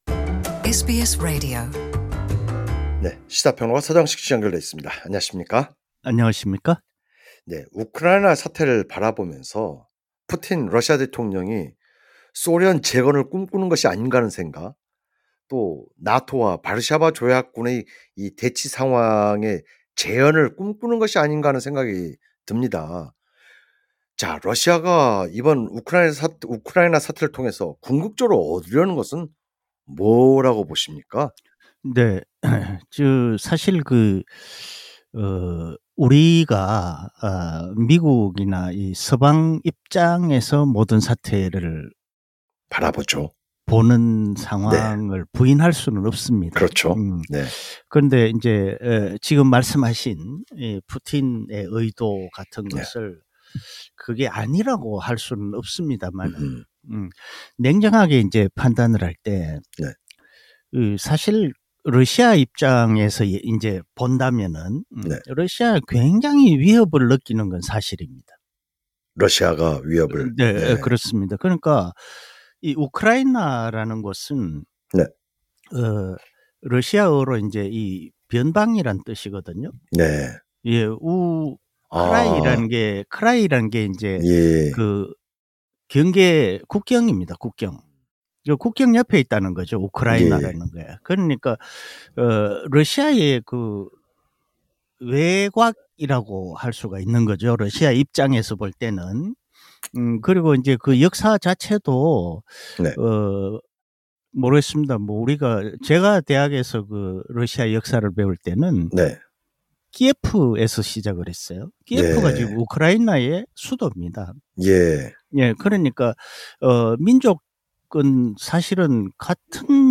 해설: 시사 평론가